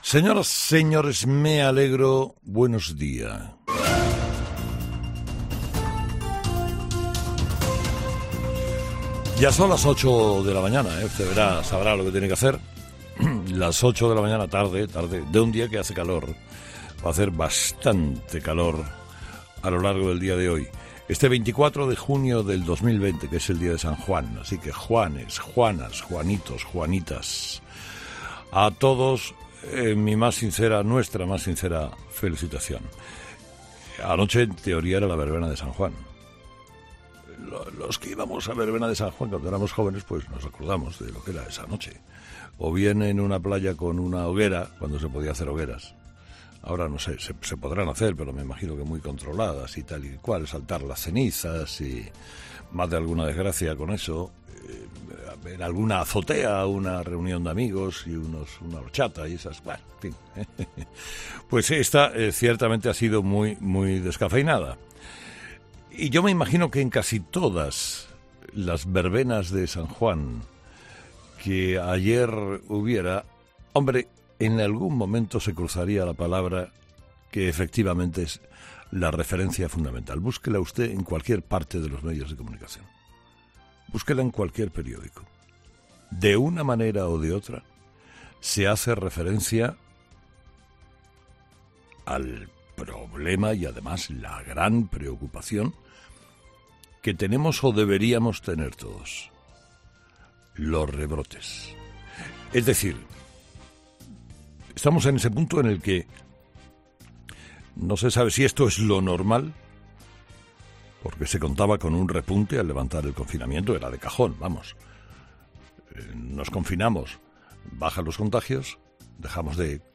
AUDIO: La pregunta que lanza Carlos Herrera al Gobierno sobre las mascarillas, entre lo mejor de 'Herrera en COPE' - Monólogo de las 8 de Herrera - COPE